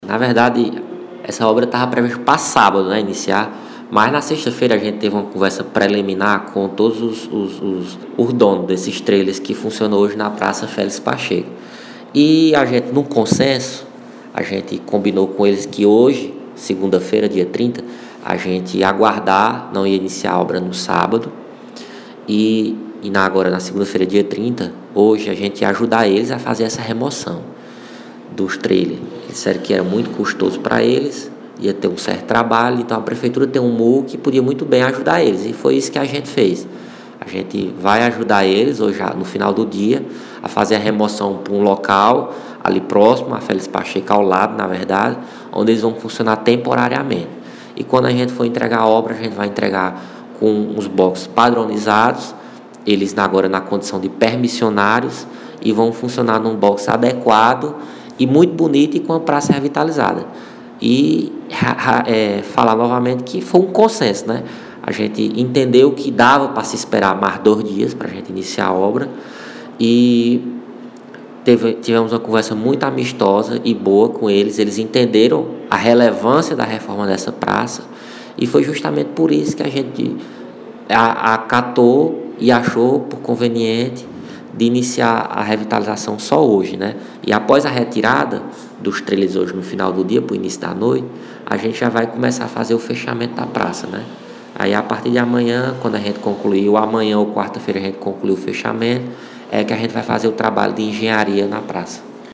Armínio Carvalho, secretário de meio Ambiente e Recursos Hídricos